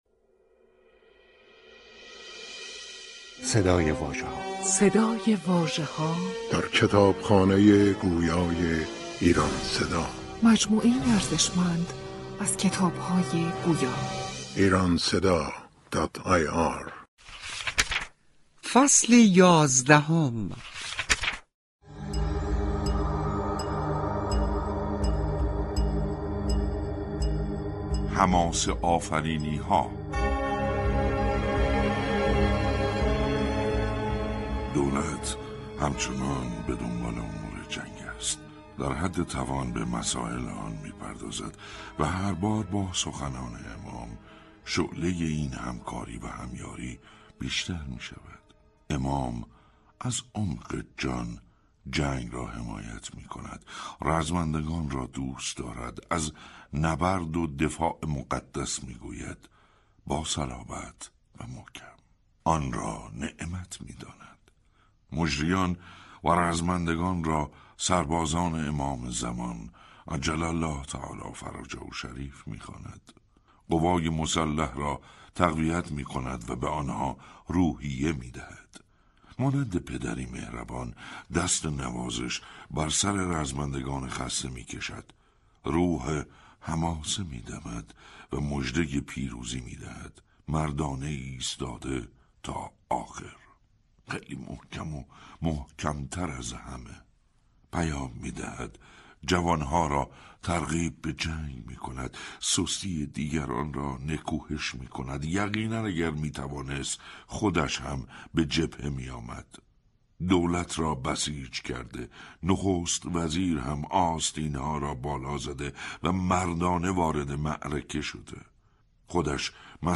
کتاب گویای «سینای شلمچه» منتشر شد
کتاب گویای «سینای شلمچه» تهیه و بر روی پایگاه کتاب گویای ایران صدا در دسترس علاقه‌مندان قرارگرفته است.